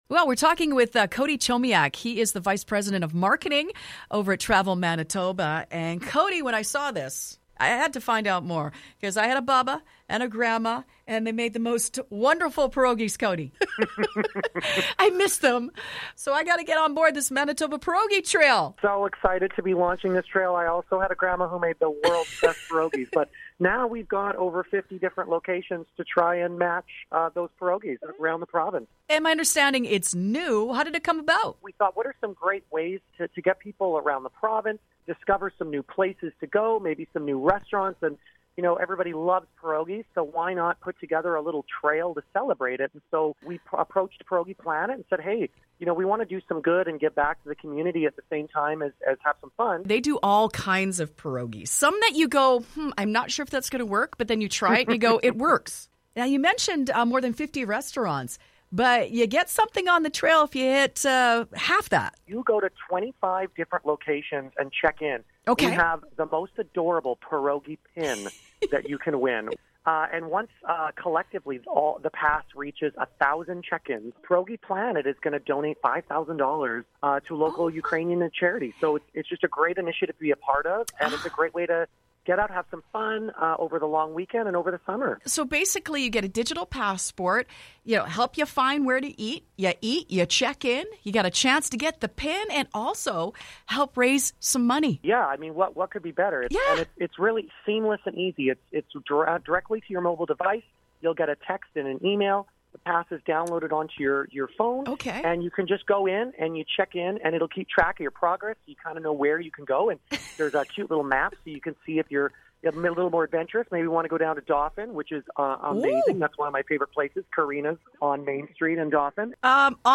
We talked w/